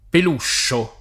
pelusce [ pel 2 šše ] o peluscio [ pel 2 ššo ] s. m.